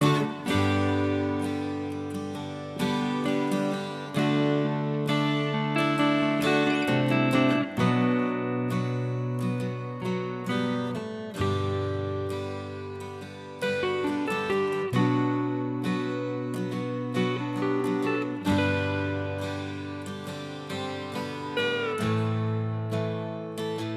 Minus 6 String Guitar Rock 5:47 Buy £1.50